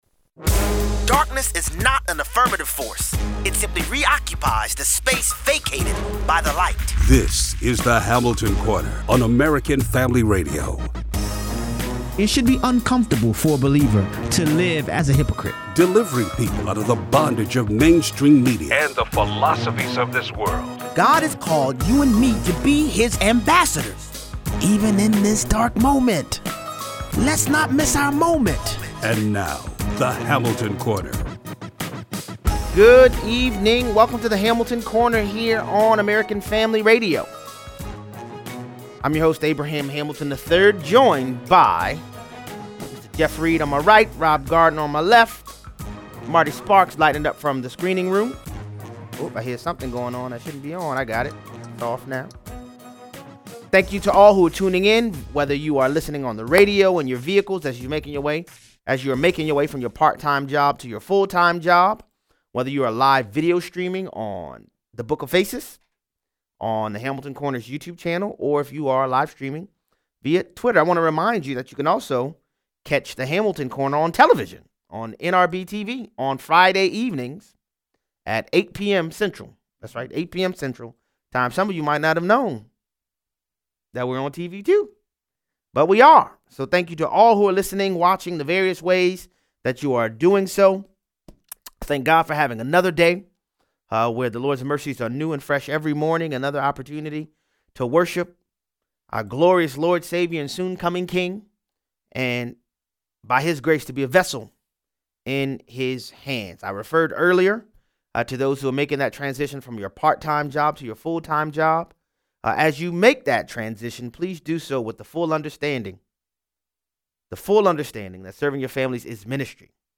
Is the fix in against Bernie Sanders again? Callers weigh in.